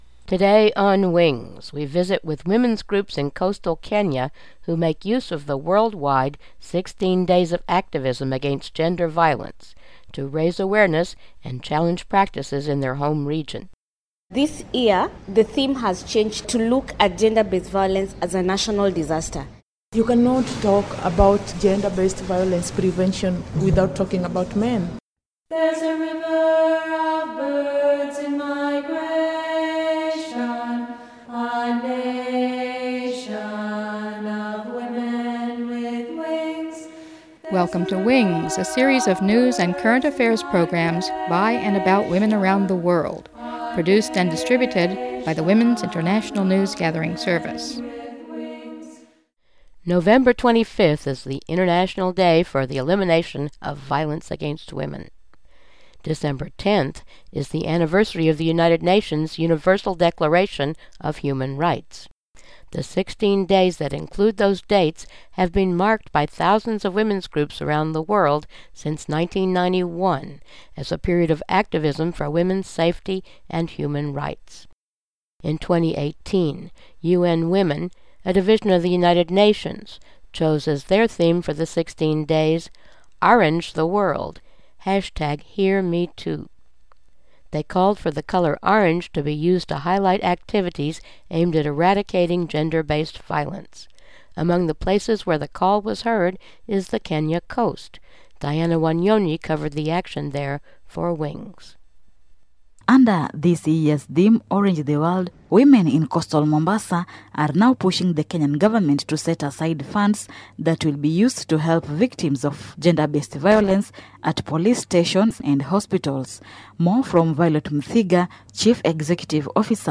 video sound about work with male sex offenders (and victims) from Coast Women media